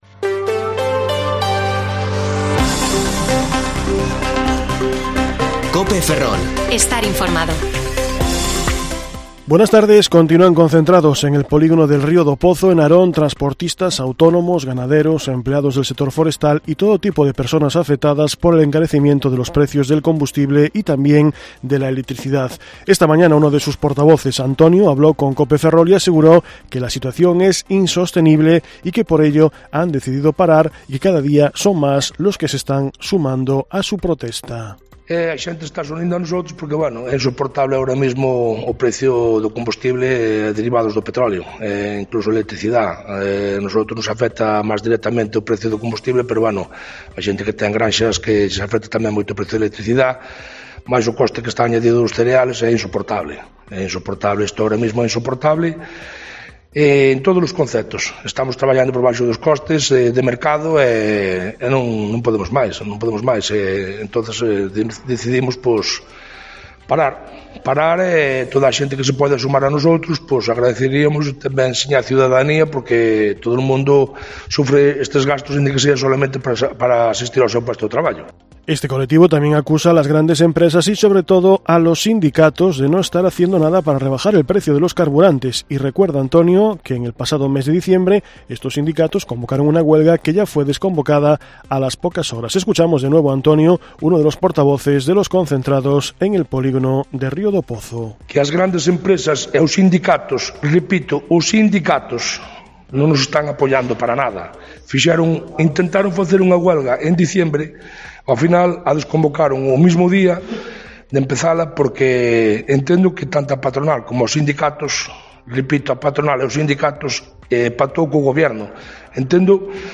Informativo Mediodía COPE Ferrol 18/3/2022 (De 14,20 a 14,30 horas)